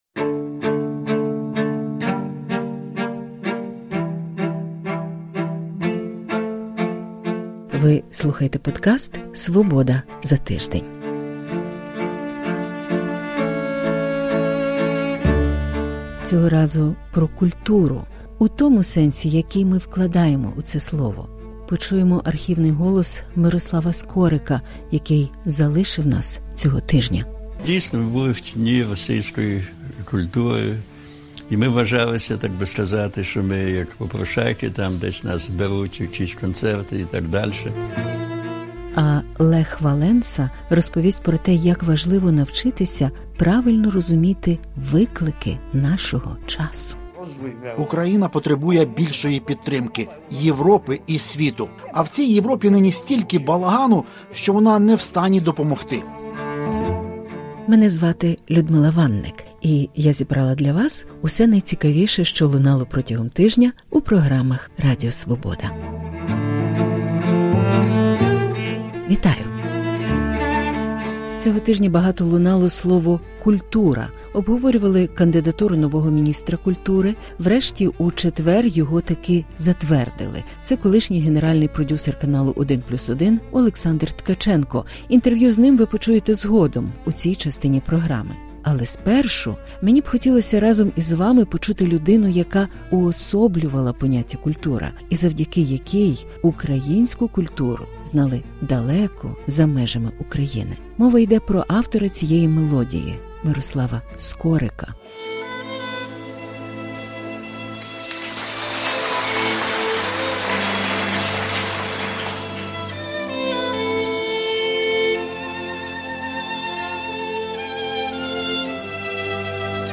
Цього разу про культуру – у тому сенсі, який ми вкладаємо у це слово – почуємо архівний голос Мирослава Скорика, який залишив нас цього тижня. А Лех Валенса розповість про те, як навчитися правильно розуміти виклики нашого часу. Як протести у США вплинули на інші країни. Де в Україні можна сховатись від коронавірусу і гарно відпочити.